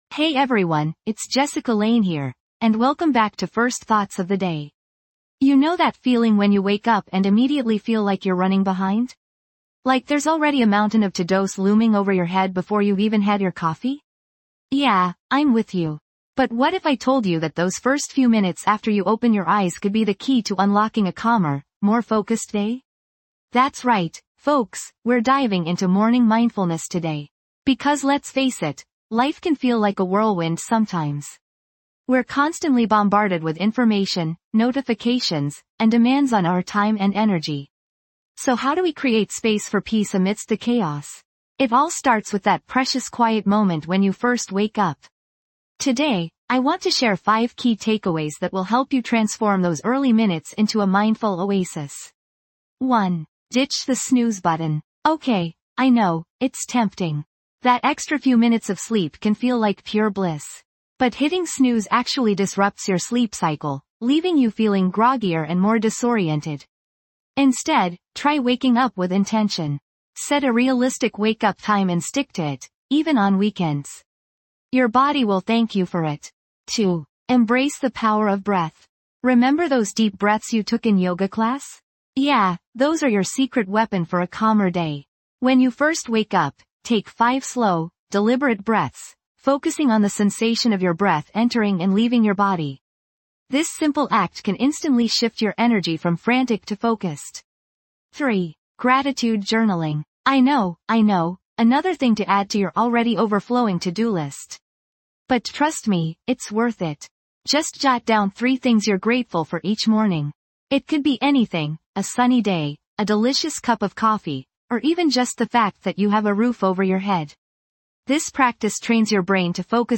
In this episode of First Thoughts of the Day Calm Morning Self Talk, we guide you through a calming meditation focused on gratitude, grounding, and visualization techniques for a stress-free and productive day ahead.